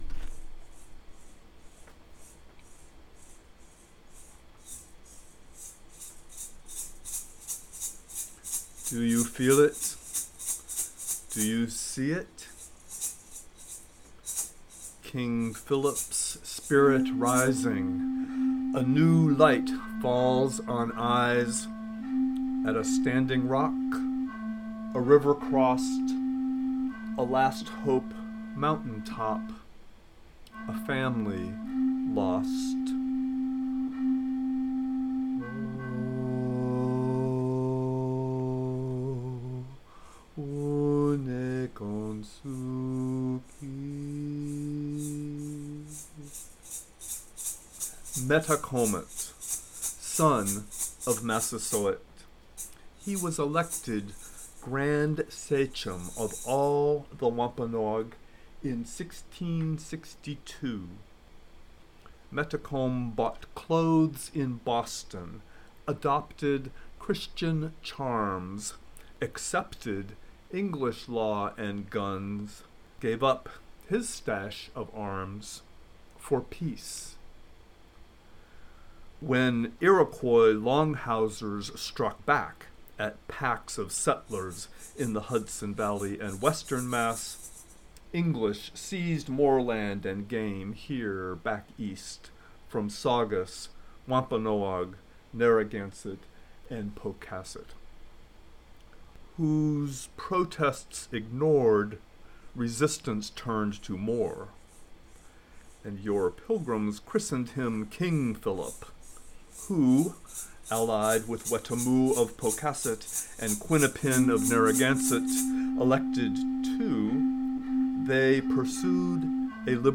The pan flute is a synth instrument from an Abelton collection. I am playing the seed rattles (a type of leather gourd I think, on sticks), which are now probably about 100 years old.